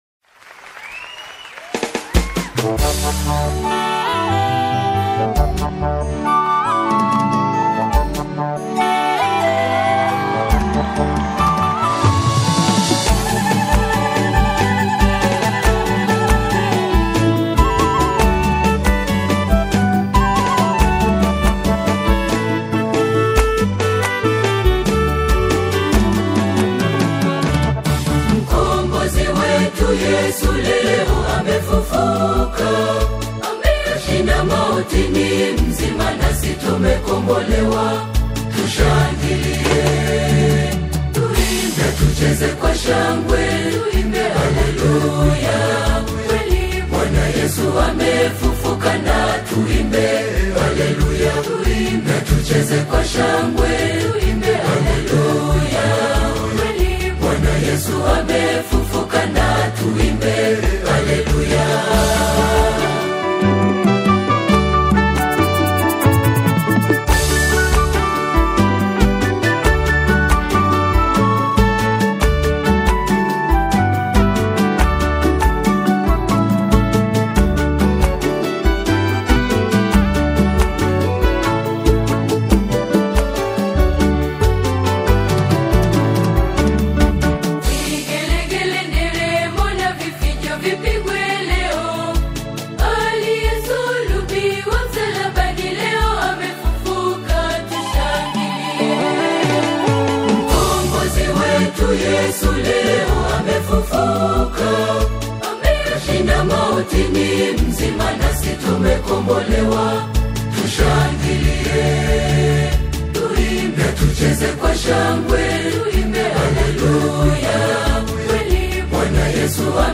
a vibrant and joyful anthem